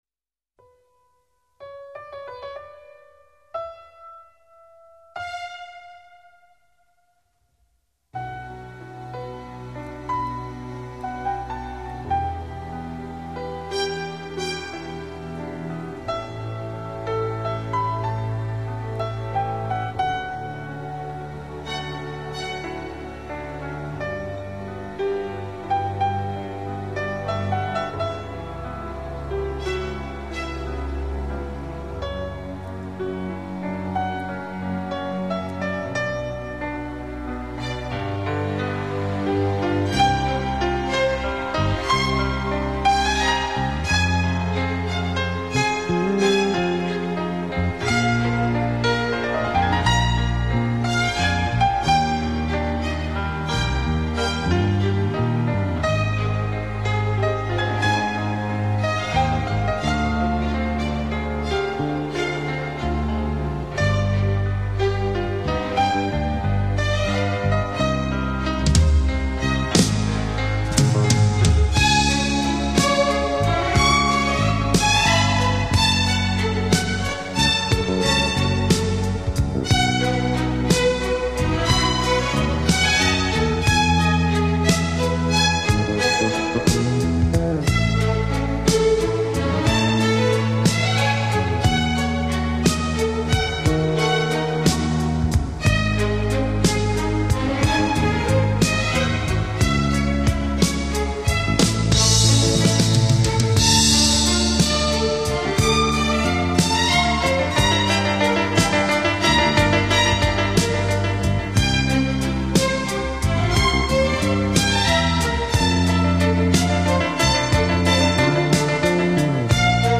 SILNAYaMELODIYaklassikavsovremennoyobrabotke.mp3